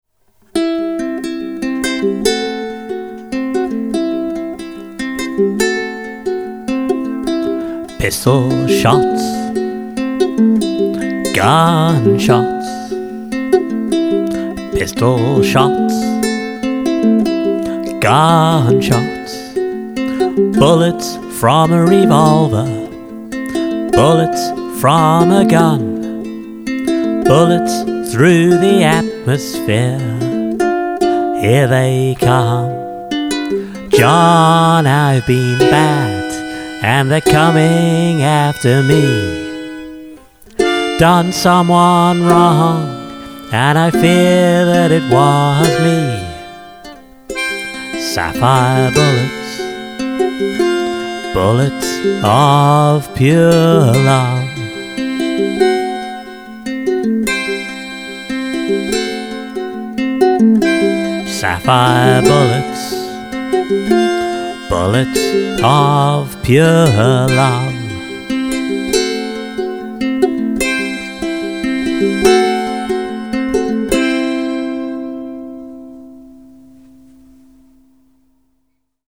Really like your fingerpicking for this.